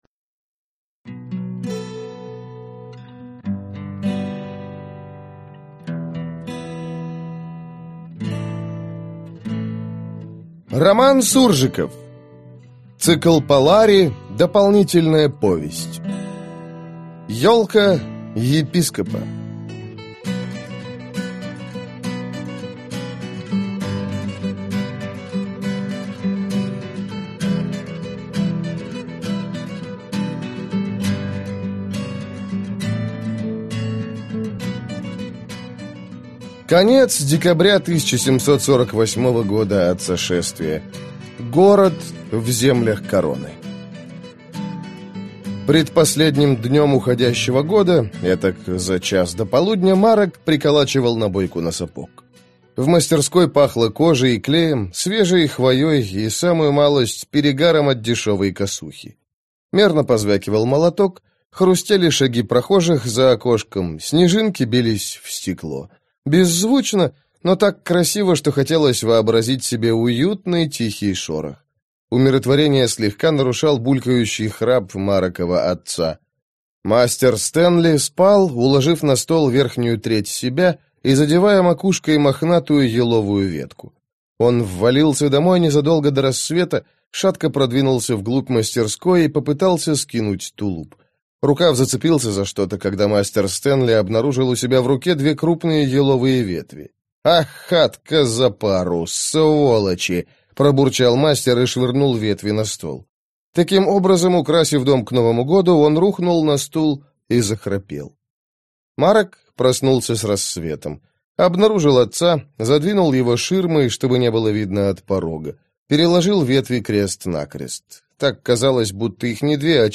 Аудиокнига Ёлка епископа